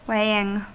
Ääntäminen
Ääntäminen US UK : IPA : /ˈweɪ.ɪŋ/ US : IPA : /ˈweɪ.ɪŋ/ Haettu sana löytyi näillä lähdekielillä: englanti Käännöksiä ei löytynyt valitulle kohdekielelle.